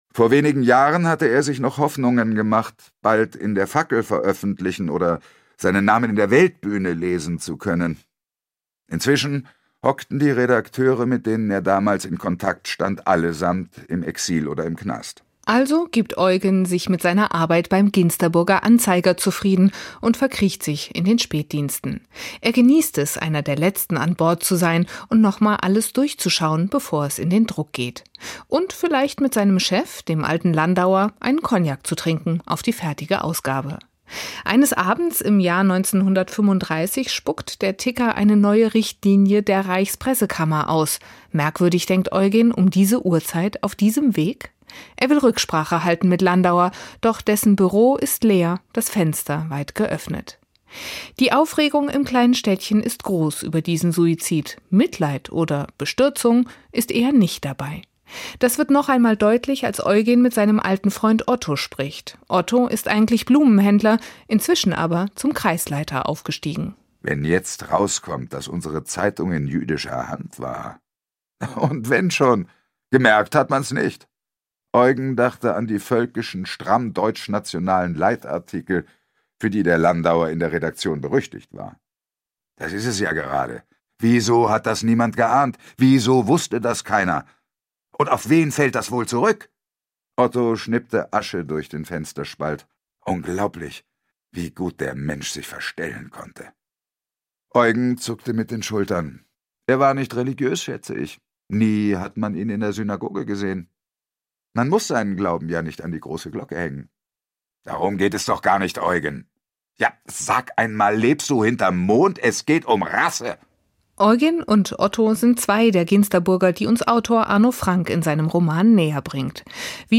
Hörbuch
Schauspieler Heikko Deutschmann gelingt es mit seiner Interpretation, dem Hörer die vielen Figuren und unterschiedlichen Charaktere sehr nahe zu bringen.